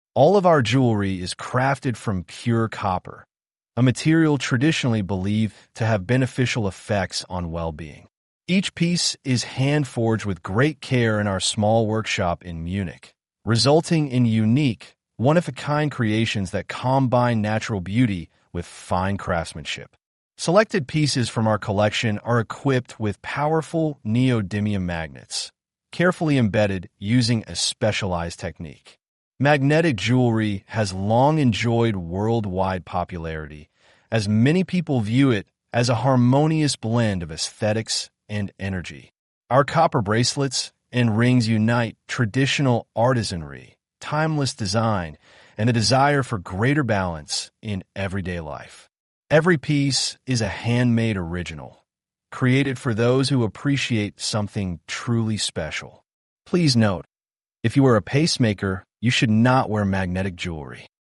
magnetic-jewelry-ttsreader.mp3